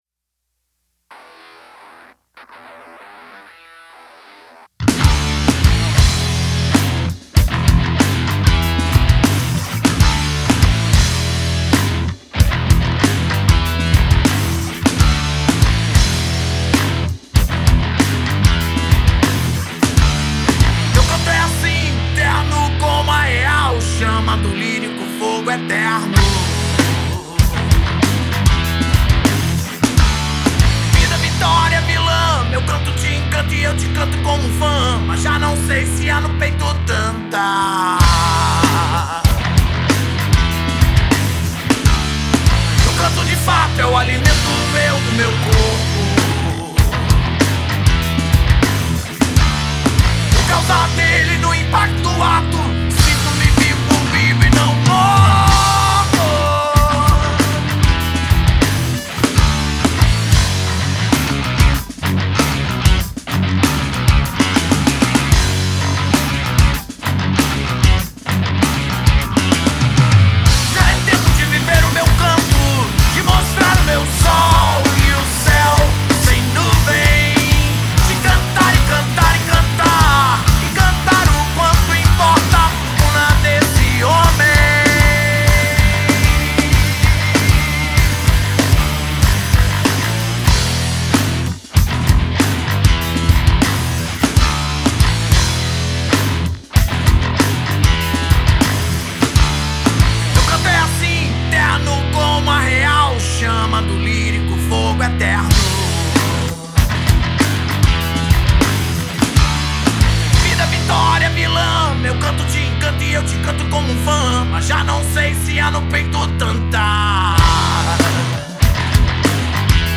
2318   03:58:00   Faixa:     Rock Nacional